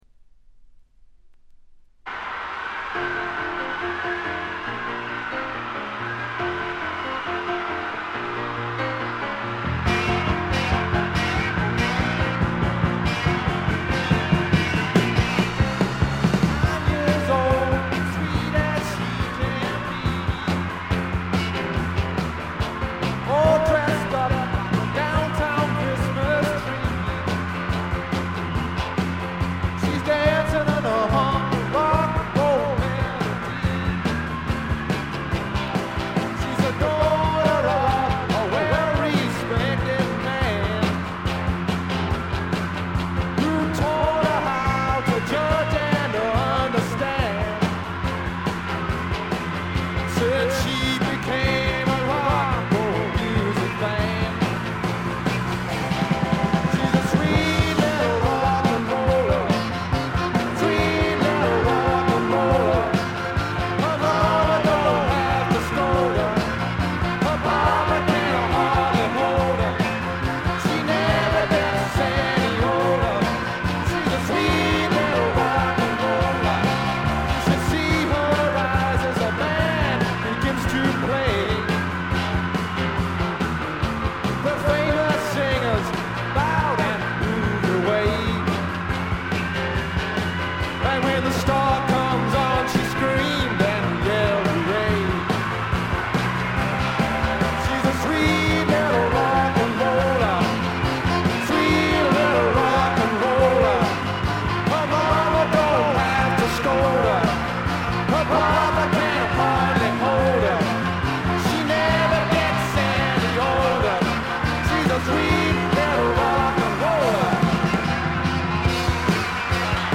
部分試聴ですが軽微なチリプチ少し。
試聴曲は現品からの取り込み音源です。